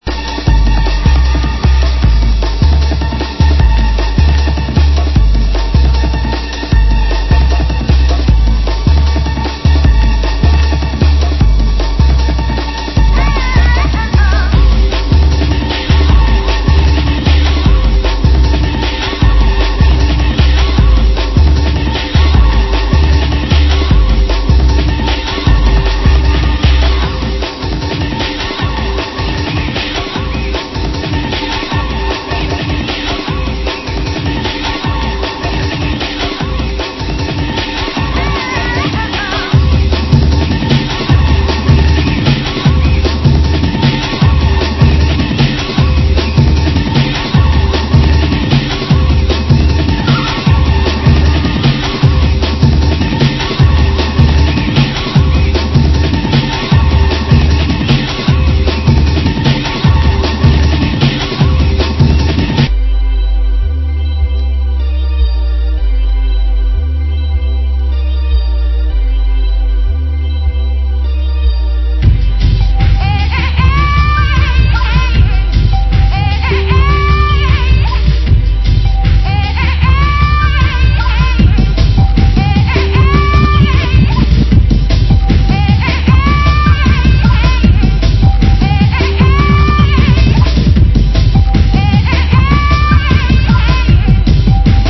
Genre Drum & Bass